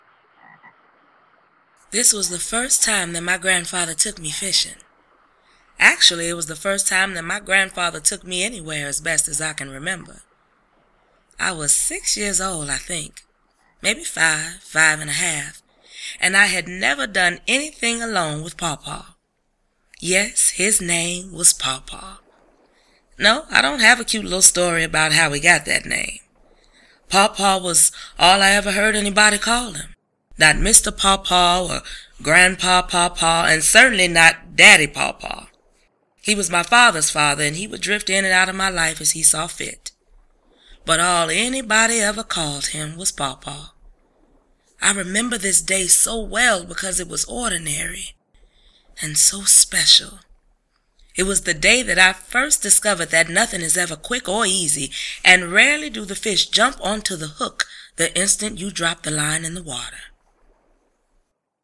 Not so much a play in the traditional sense, “Beneath A Dark Sky” is storytelling in it’s most ancient and powerful form.